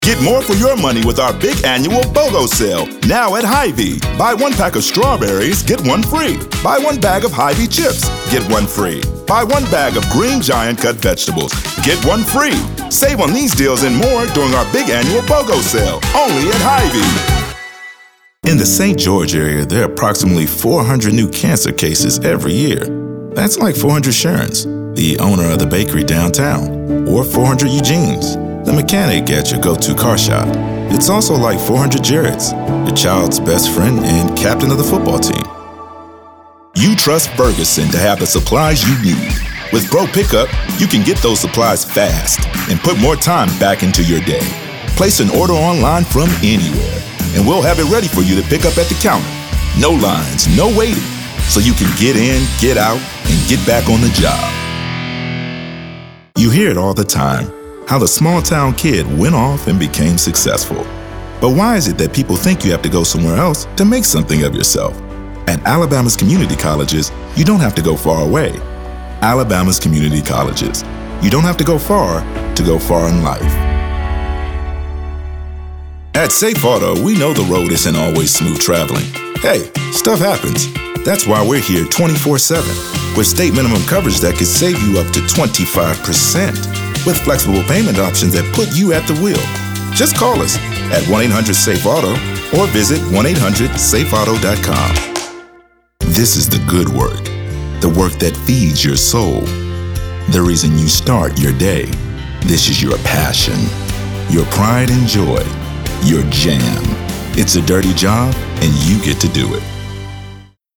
African American, announcer, authoritative, Booming, confident, Deep Voice, friendly, genuine, gravelly, Gravitas, high-energy, informative, middle-age, midlife, perky, professional, retail, upbeat